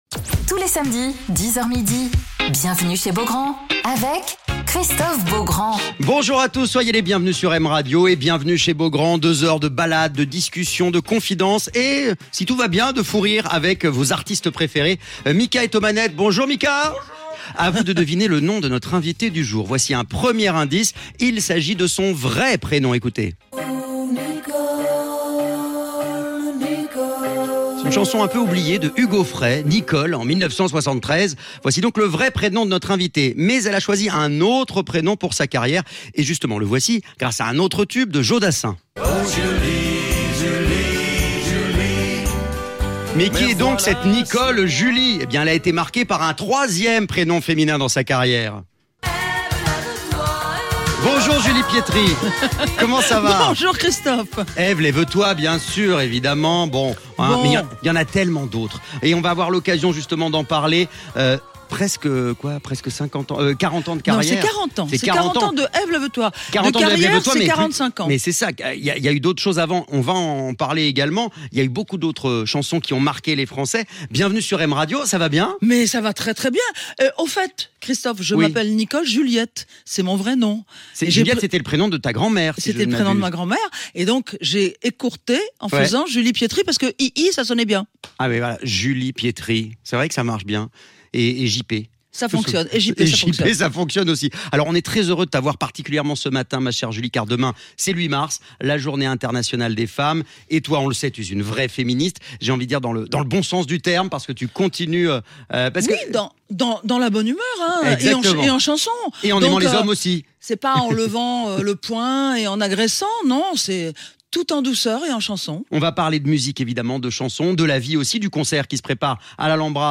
Alors qu'elle prépare la sortie d'un nouvel album "Iconic Résonance" pour ses 50 ans de carrière et une tournée qui passera par l'Alhambra à Paris (4 avril), Julie Pietri est l'invitée de Christophe Beaugrand sur M Radio !